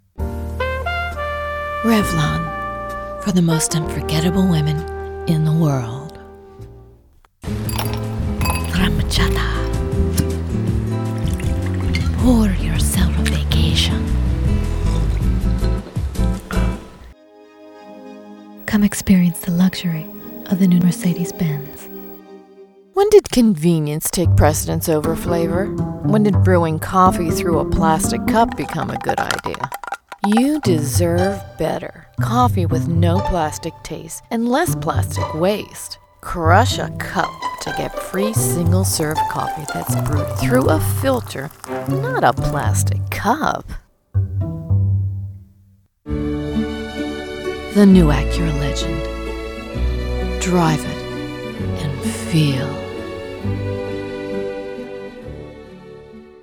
US American, female voice
mid-atlantic
Sprechprobe: Industrie (Muttersprache):